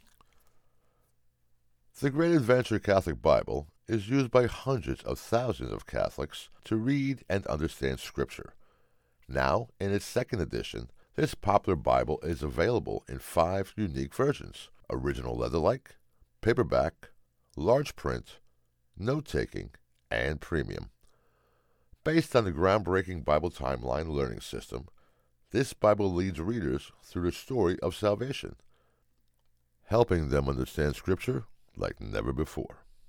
Voice is Deep, Distinctive, and Authoritative, with a Commanding Presence that exudes Confidence and Power.
Natural Speak